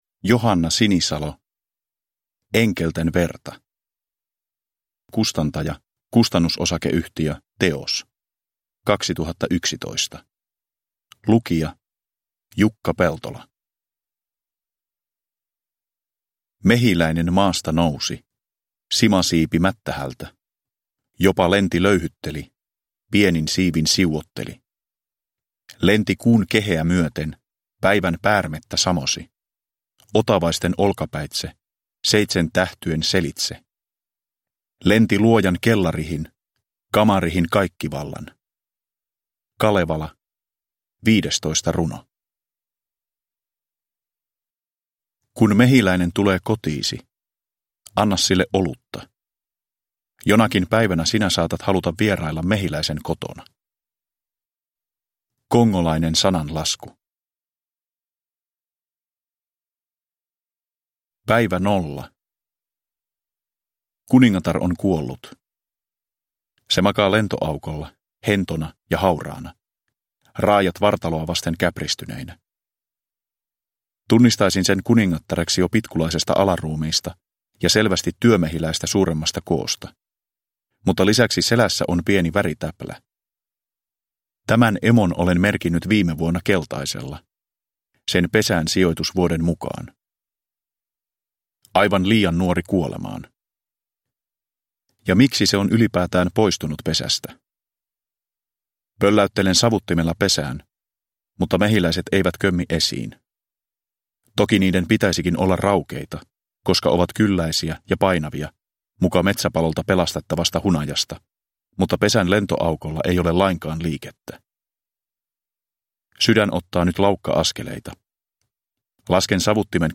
Enkelten verta – Ljudbok – Laddas ner